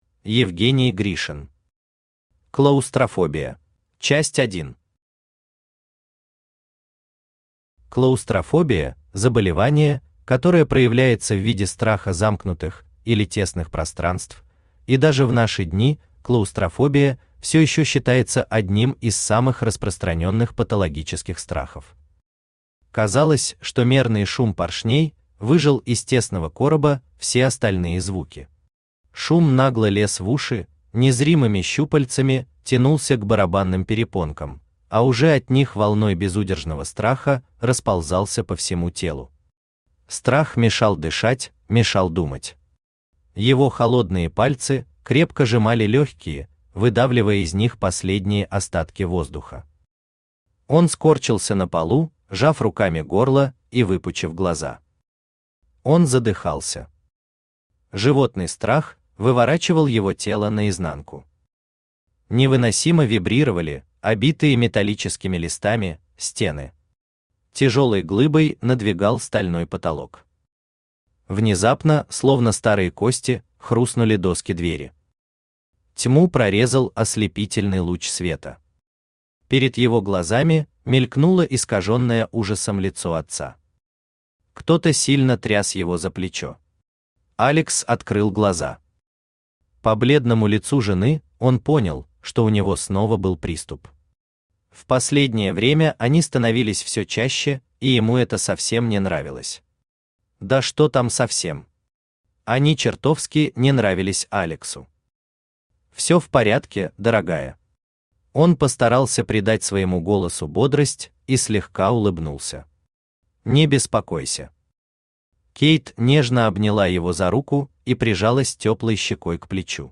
Аудиокнига Клаустрофобия | Библиотека аудиокниг
Aудиокнига Клаустрофобия Автор Евгений Гришин Читает аудиокнигу Авточтец ЛитРес.